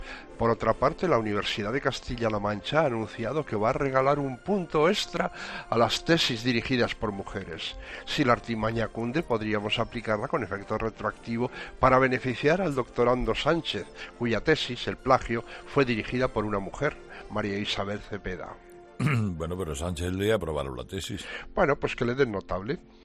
"Pues que le den notable", ha respondido de nuevo el tertuliano entre risas.